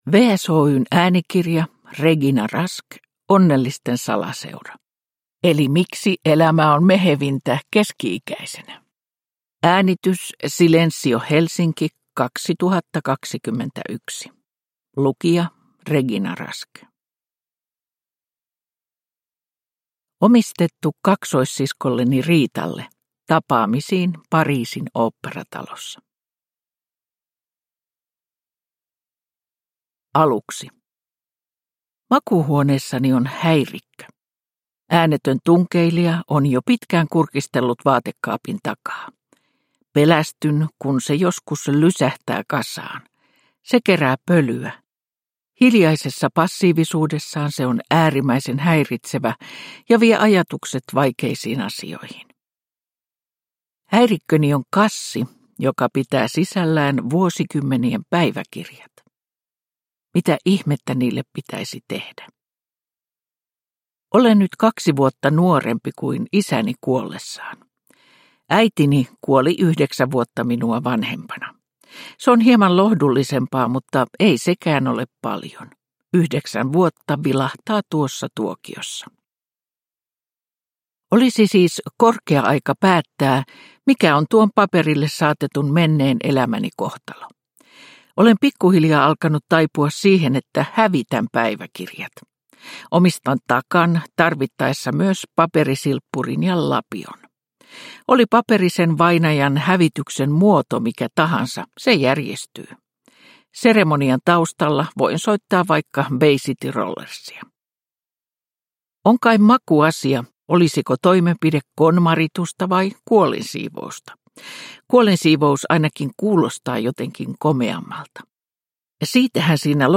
Onnellisten salaseura – Ljudbok – Laddas ner